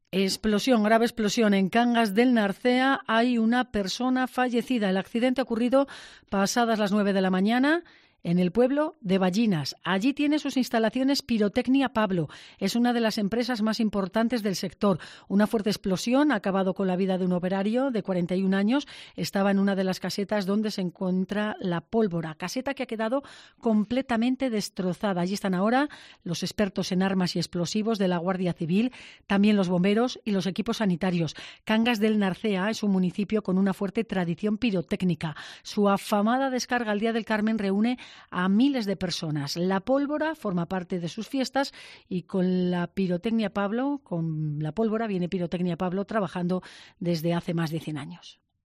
Así hemos contado en COPE la explosión en Pirotecnia Pablo (Cangas el Narcea) con un fallecido